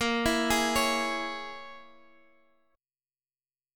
A#m7b5 chord